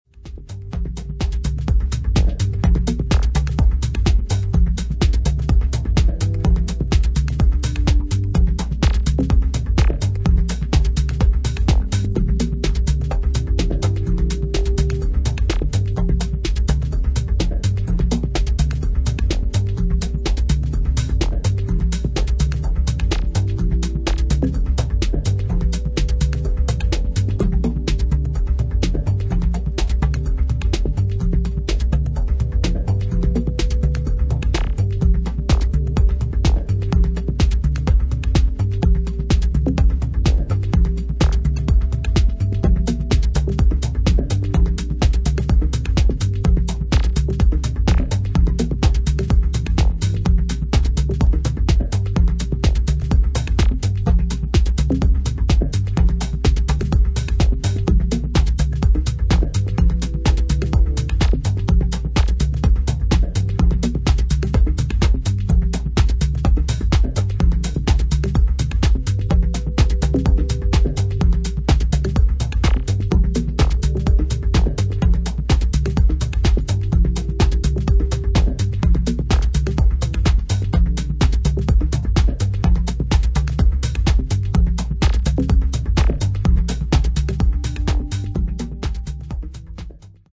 より一段とリズムに着目し、ドライブ感あるグルーヴで一音一音意識を傾けさせる一曲に仕上げています。